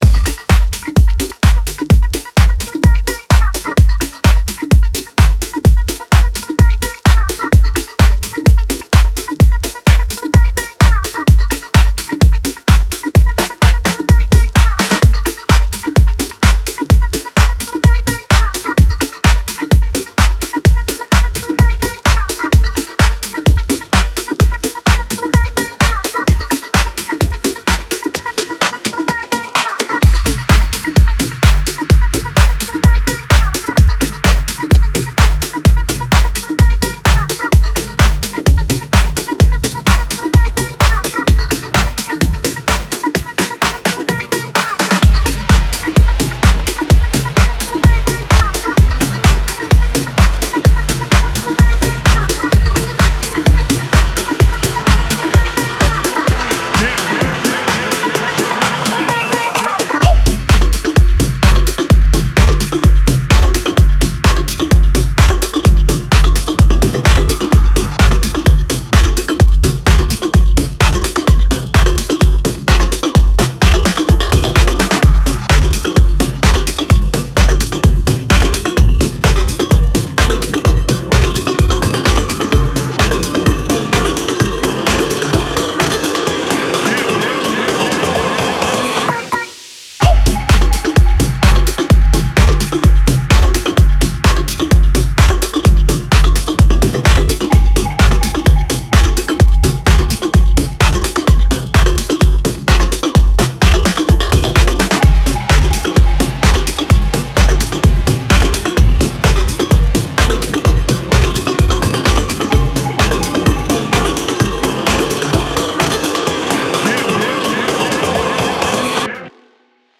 Genre House